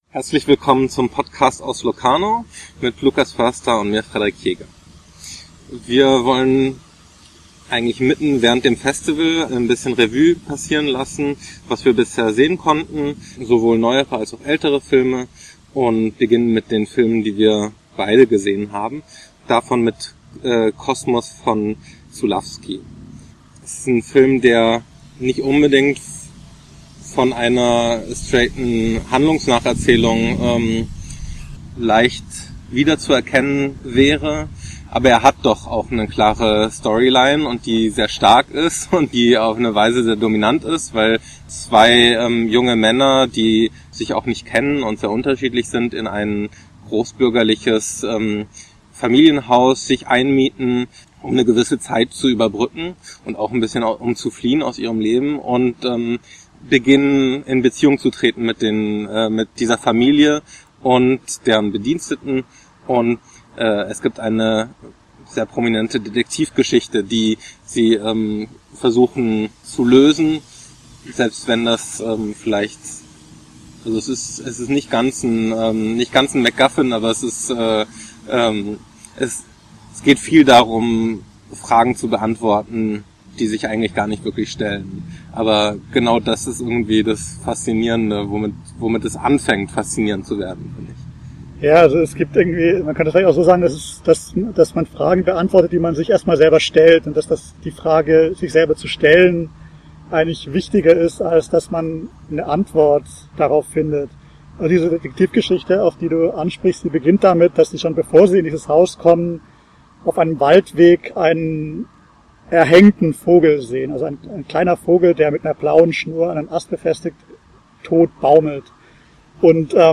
Bitte entschuldigen Sie das Hintergrundrauschen. Wir waren draußen.